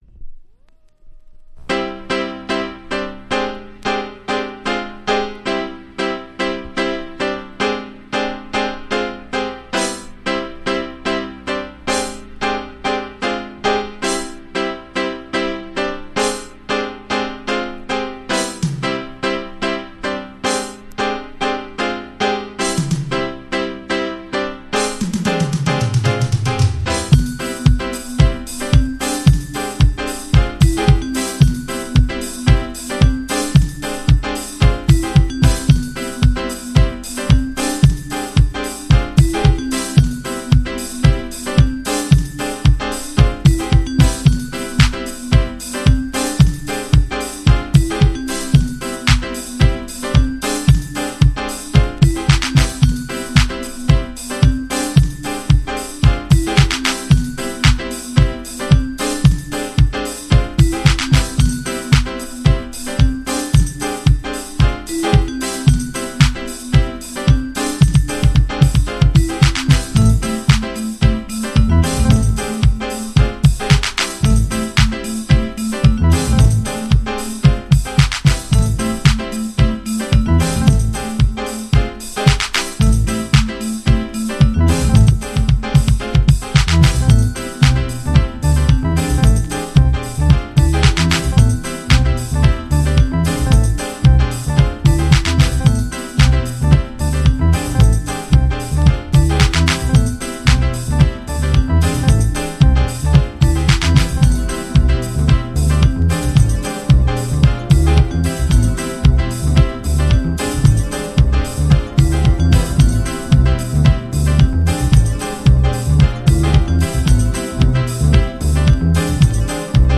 TOP > Early House / 90's Techno > VARIOUS